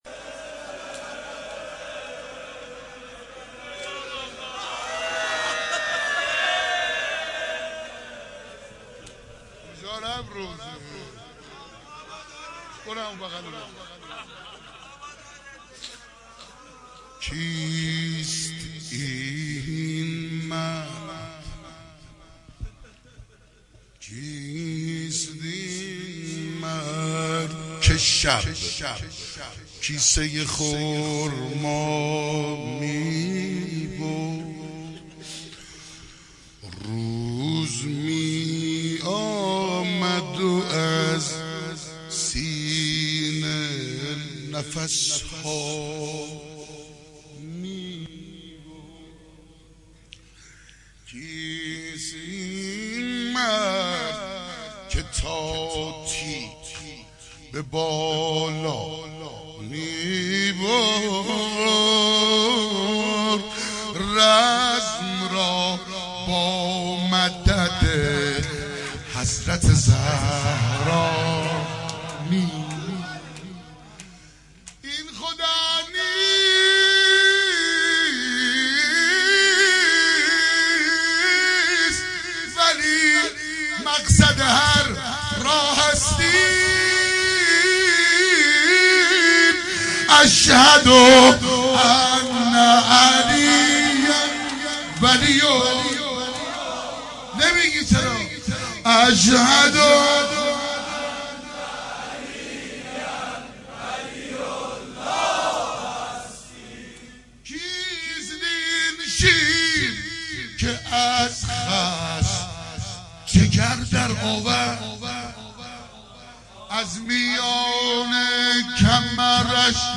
روضه شهادت حضرت علی